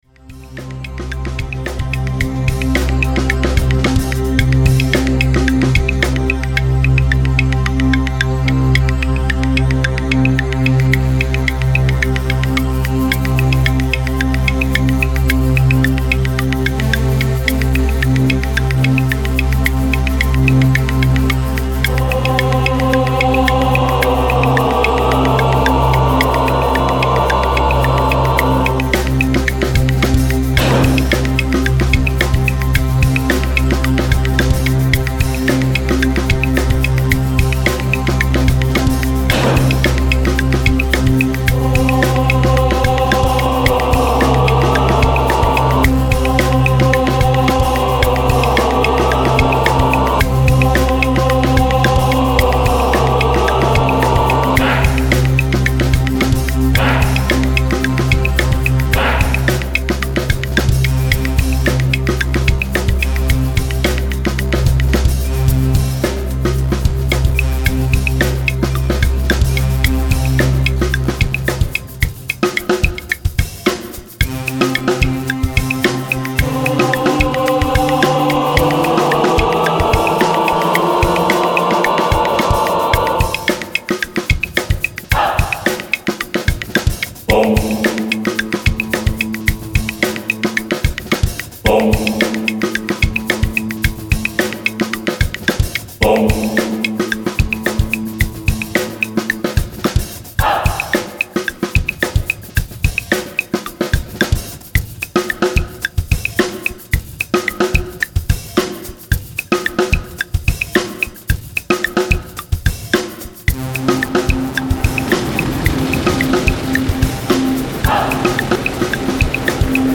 Ambiante